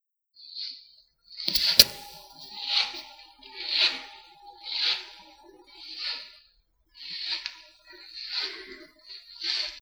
Lijado de superficie
Grabación sonora del sonido del lijado a mano de una superficie
Sonidos: Acciones humanas
Sonidos: Industria